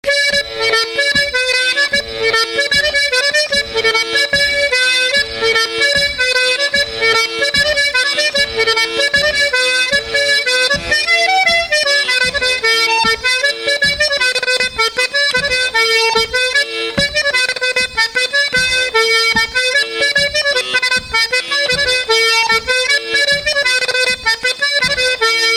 Résumé instrumental
Pièce musicale inédite